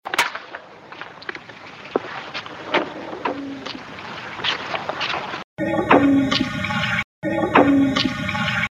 "Sigh"- This occurred in one of the hallways as I walking between rooms. It sounds feminine but it may not be a "sigh". It may be a moan or an attempted word as there is a little bit of it before the loud bang (which is likely a door). Original X1, cleaned + amplified X2.
hallwaysigh.mp3